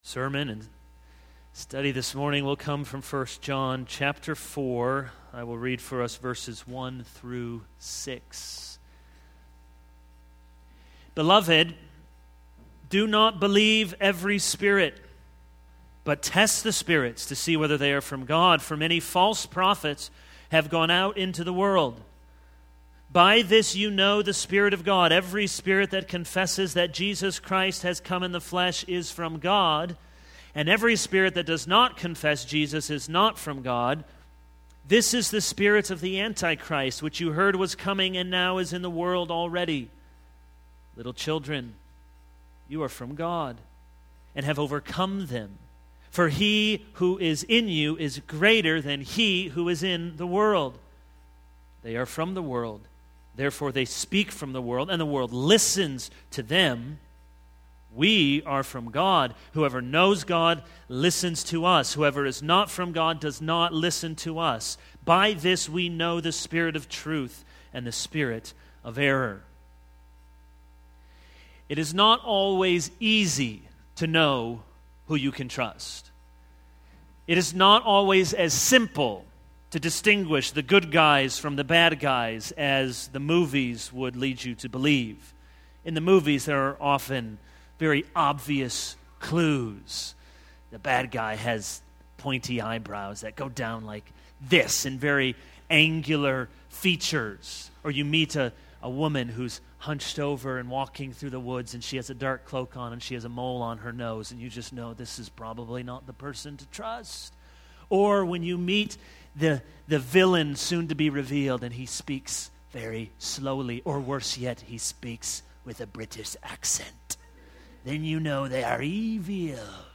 This is a sermon on 1 John 4:1-6.